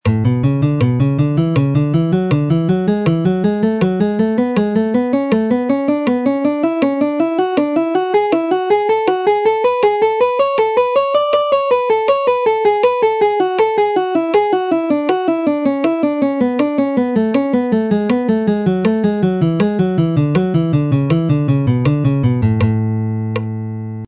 Now onto the next part of this lesson which is a sequence of four using major scale pattern #5, a nice three note per string scale pattern that lends itself nicely to these kinds of sequences (have a listen to the audio sample below).
This pattern continues up to the tenth fret at which point we simply reverse the pattern and start descending the scale.
Sequence of four guitar exercise